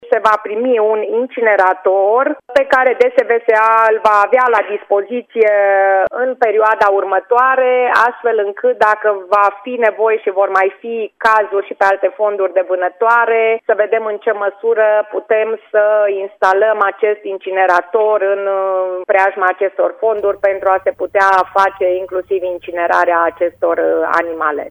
Prefectul Liliana Oneț spune că mistreții morți au fost adunați și îngropați și că, cel târziu săptămâna viitoare, în Timiș va fi adus un incinerator, pentru ca, dacă mai apar cadavre, să poată fi incinerate.